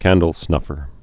(kăndl-snŭfər)